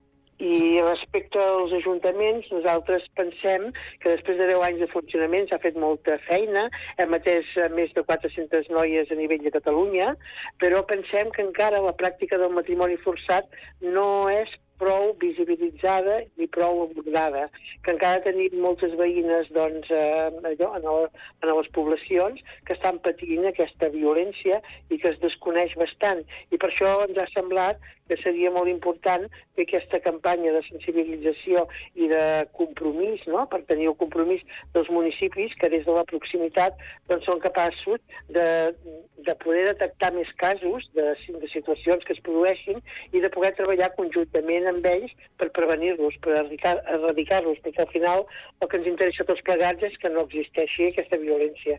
Entrevistes Supermatí